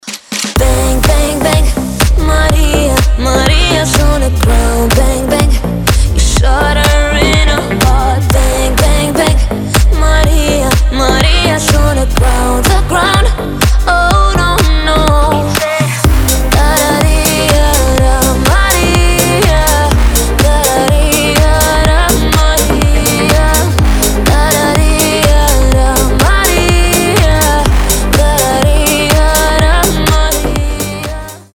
deep house
Dance Pop
красивый женский голос